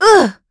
Valance-Vox_Damage_02.wav